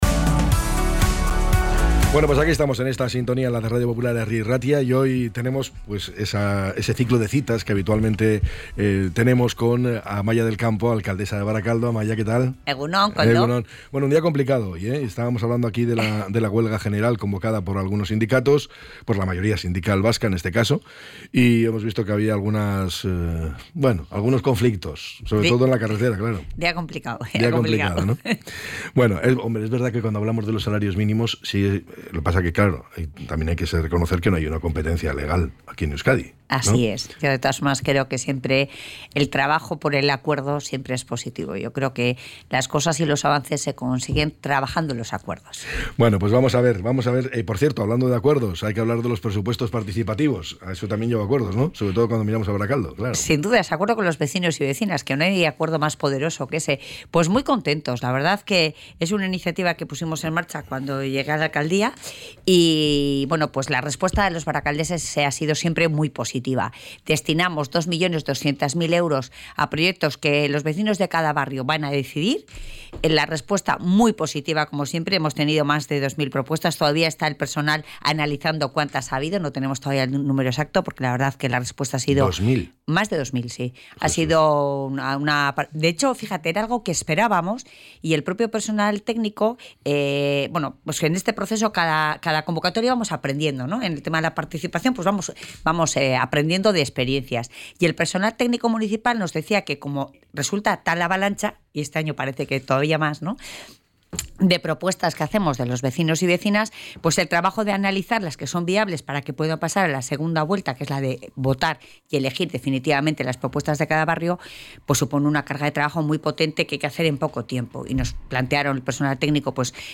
ENTREV.-AMAIA-DEL-CAMPO.mp3